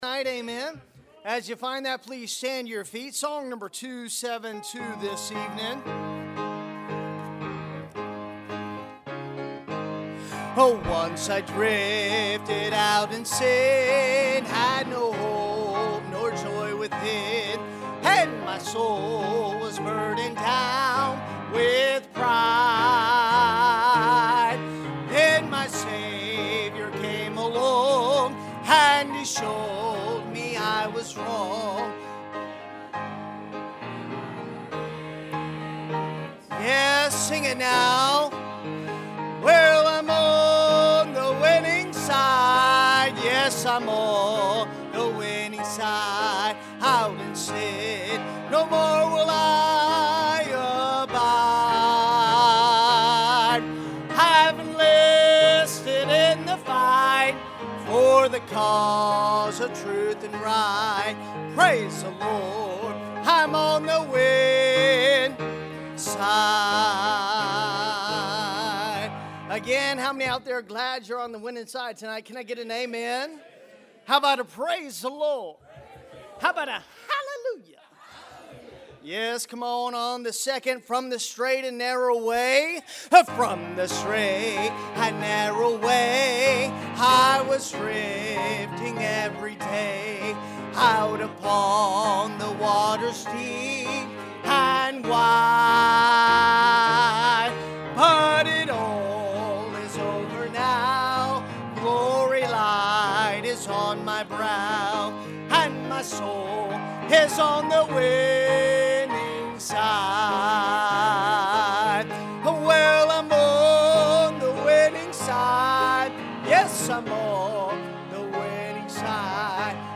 Battles | Tuesday Night Revival